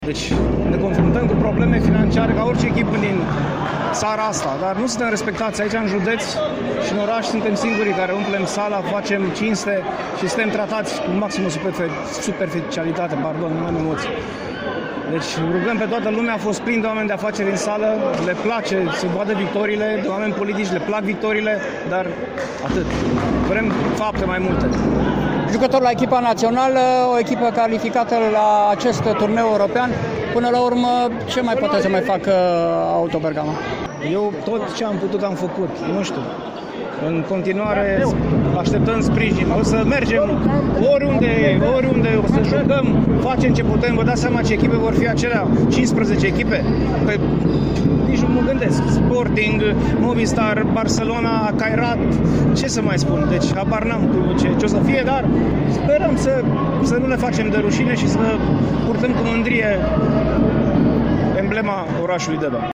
interviuri